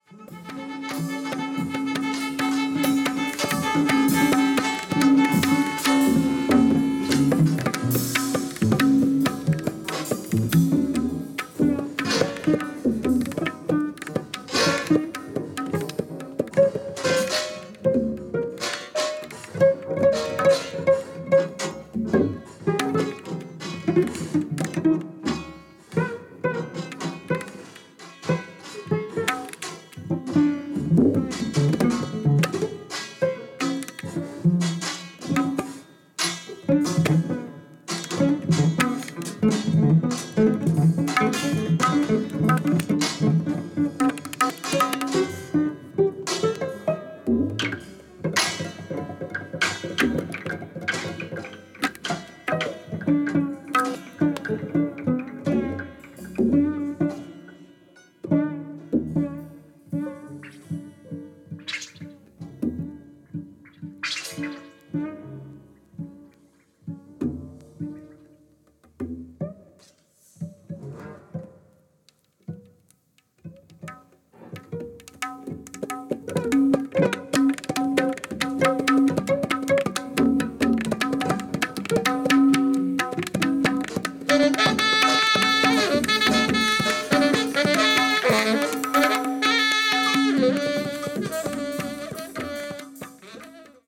Cello
Bass
Drums
Saxophone
Violin
Piano